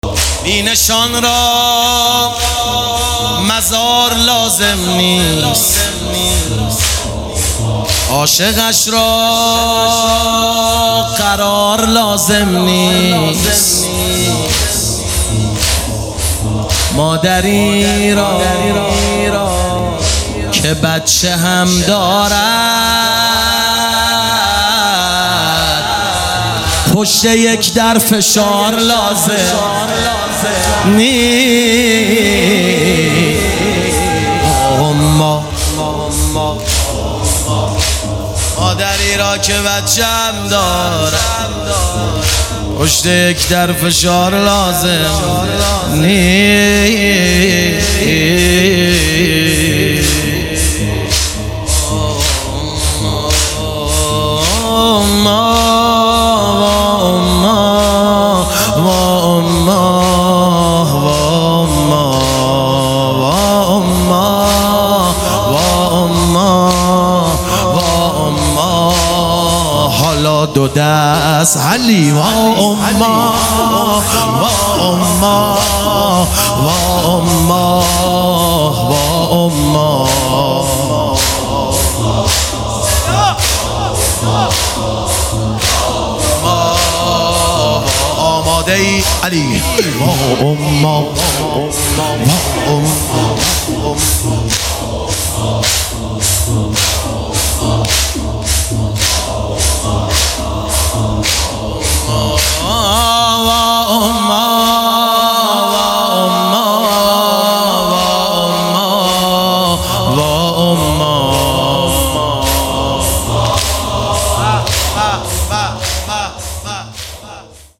ایام فاطمیه 1399 | هیئت معظم کربلا کرمان